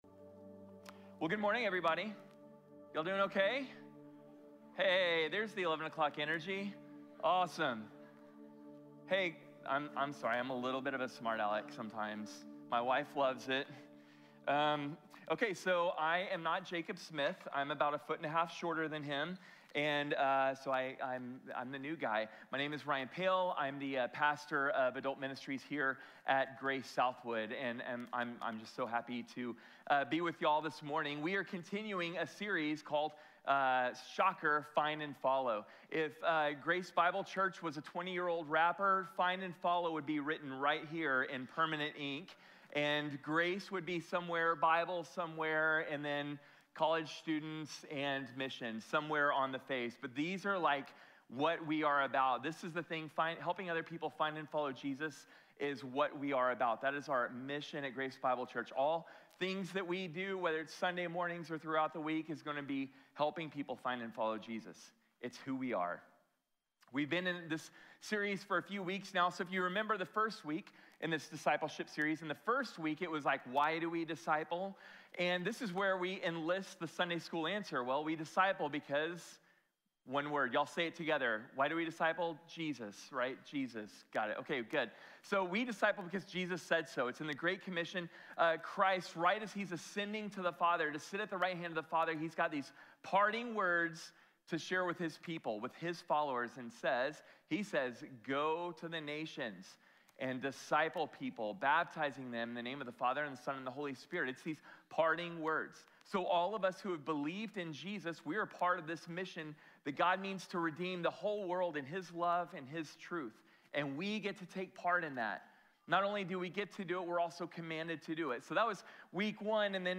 Retrato de un discípulo | Sermón | Iglesia Bíblica de la Gracia